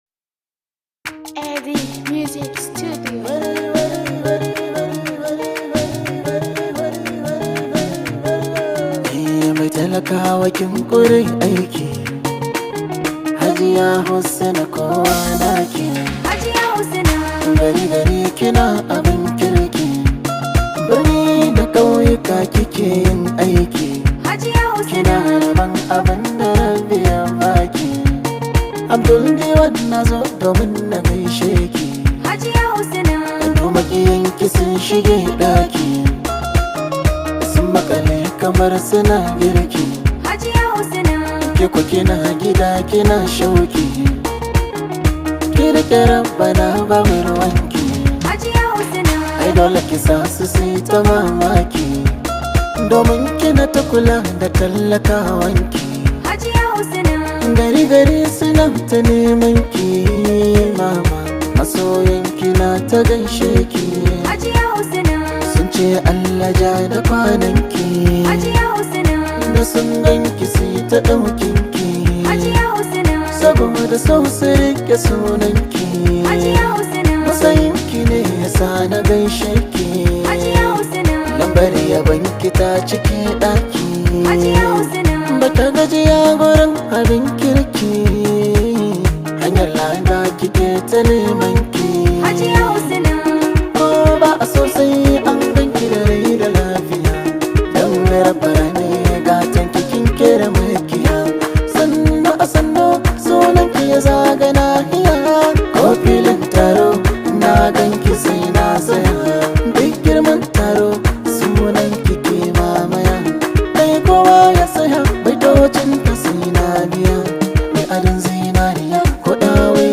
Powerful Arewa Track
Hausa Singer